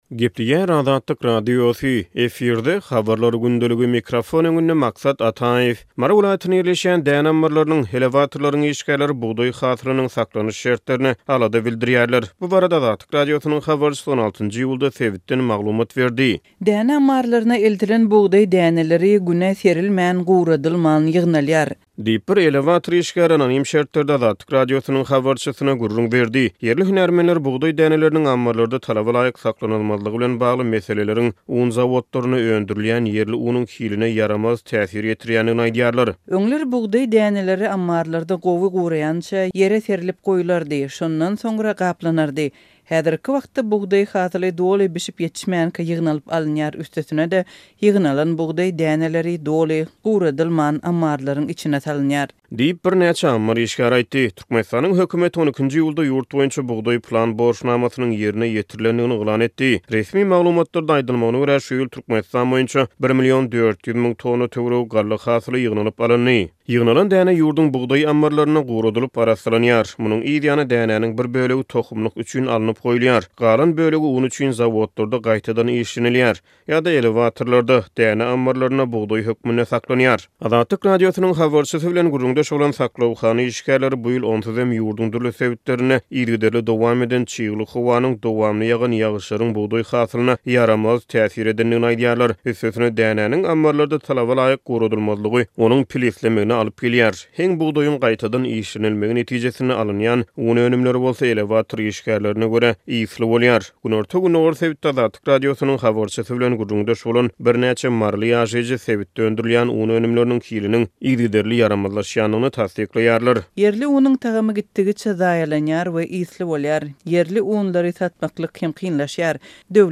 Mary welaýatynda ýerleşýän däne ammarlarynyň, elewatorlaryň işgärleri bugdaý hasylynyň saklanyş şertlerine alada bildirýärler. Bu barada Azatlyk Radiosynyň habarçysy 16-njy iýulda sebitden maglumat berdi.